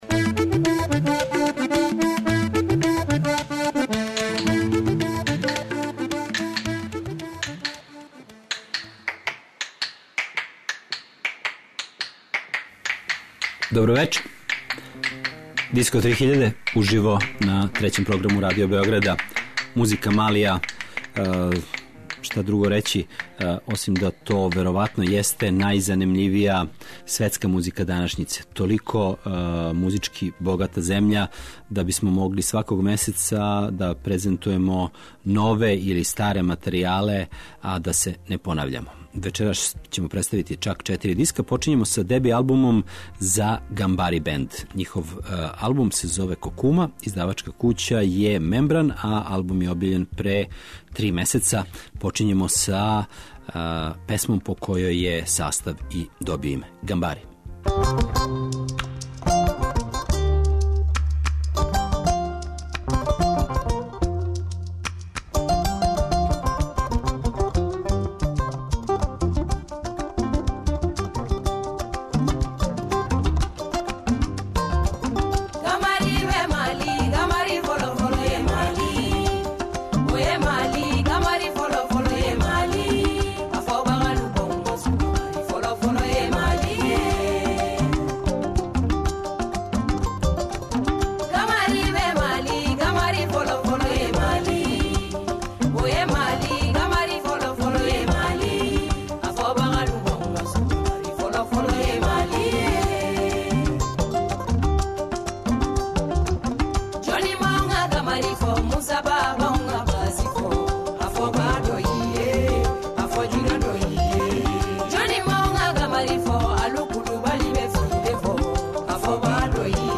малијске традиционалне и модерне музике
world music